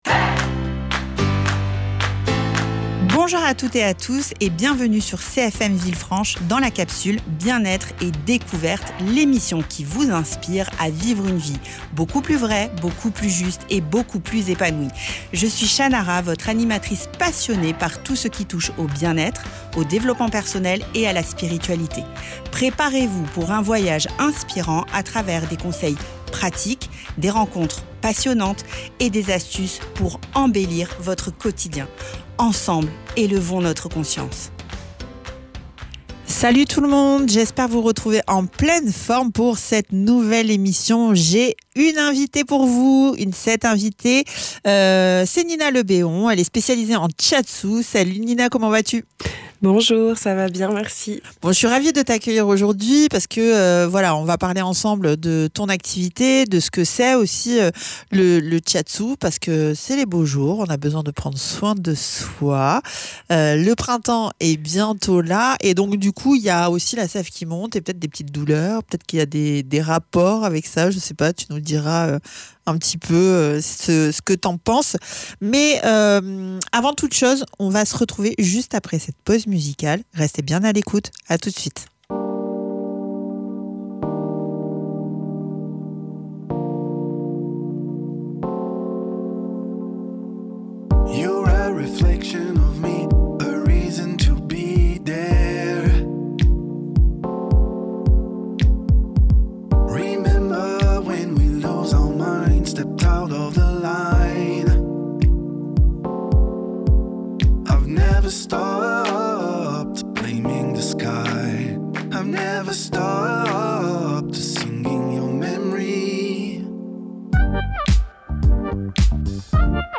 Aujourd’hui, dans l’émission Bien-être et Découverte, j’ai le plaisir d’accueillir une nouvelle invitée, spécialisée en shiatsu, une pratique japonaise qui vise à rééquilibrer les énergies du corps par le toucher. Avec elle, nous allons découvrir comment le shiatsu peut aider à relâcher les tensions, apaiser le mental et retrouver un véritable mieux-être au quotidien.